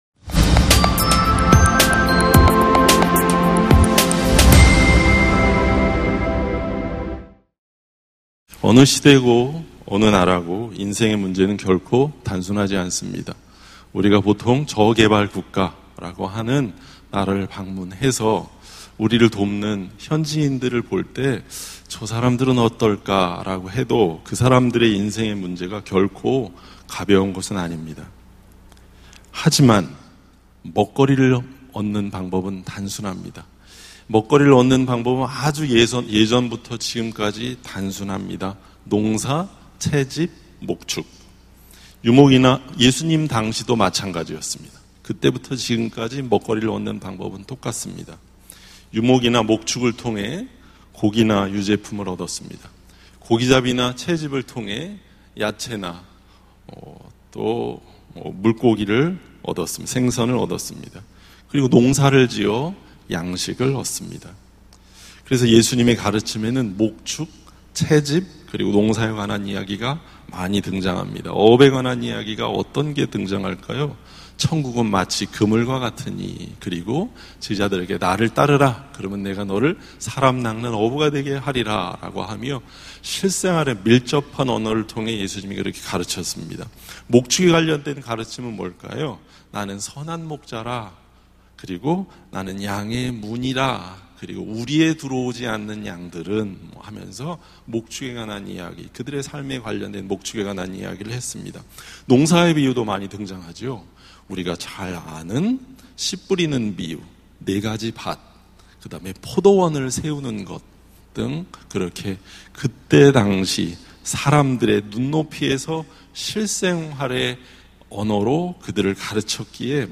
[수요향수예배]